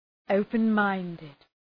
Shkrimi fonetik{‘əʋpən,maındıd}
open-minded.mp3